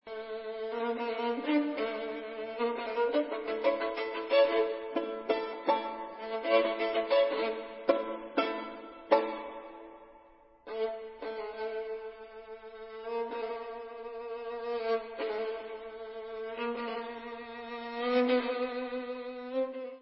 sledovat novinky v kategorii Vážná hudba